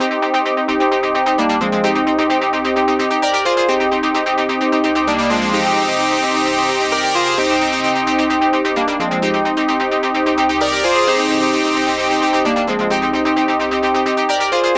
FILTER BED 6.wav